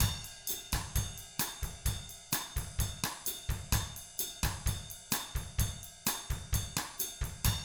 129BOSSAT2-R.wav